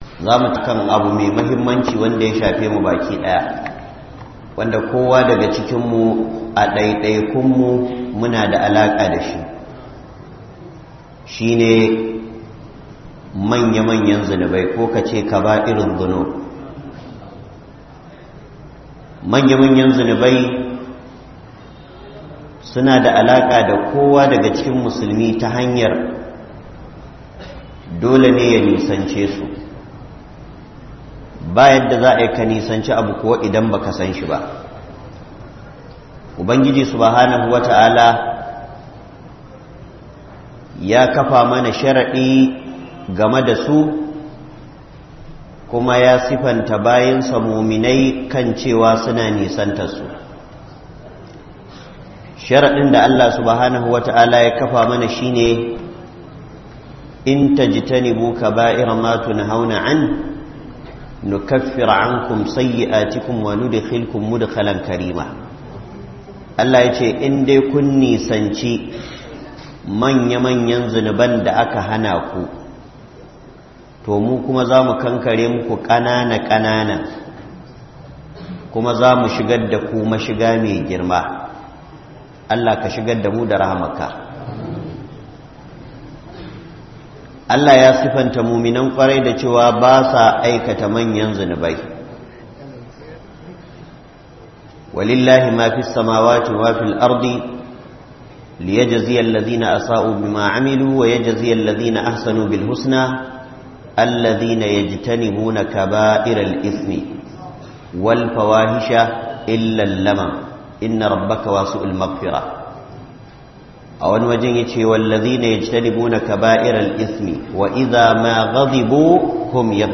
MANYA MANYAN ZUNUBAI - MUHADARA